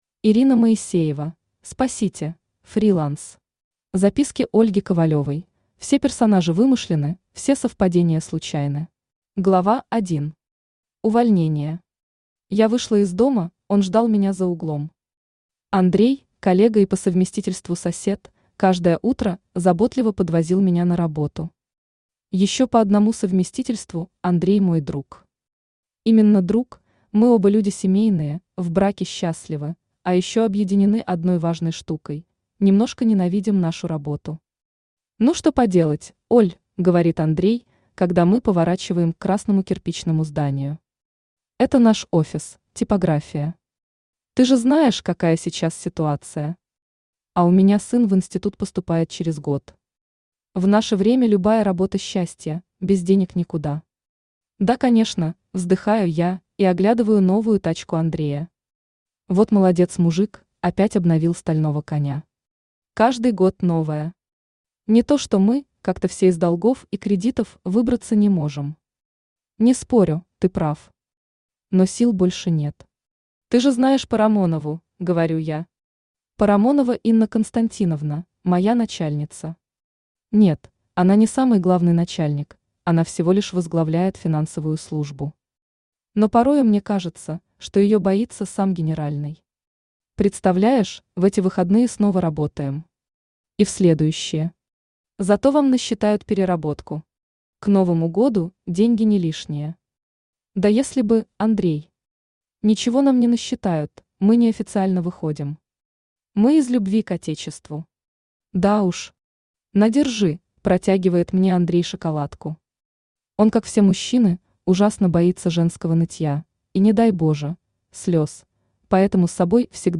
Фриланс Автор Ирина Моисеева Читает аудиокнигу Авточтец ЛитРес. Прослушать и бесплатно скачать фрагмент аудиокниги